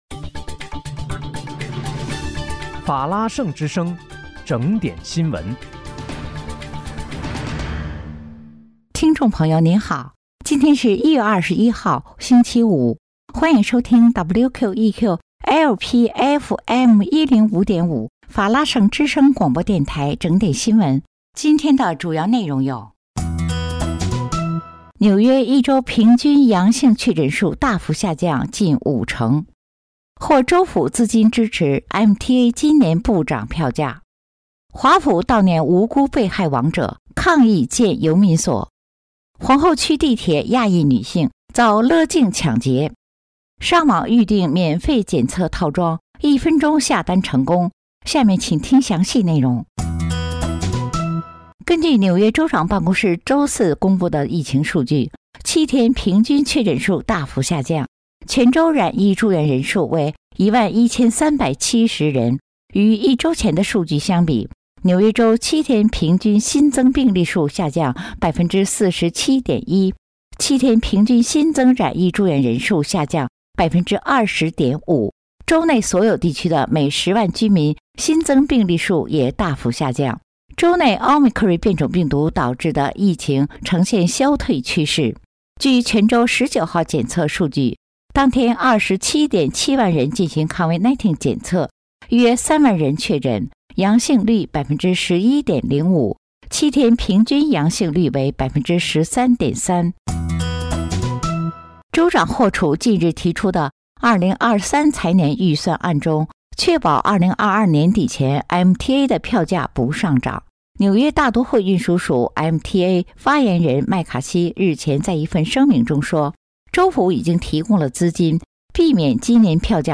1月21日（星期五）纽约整点新闻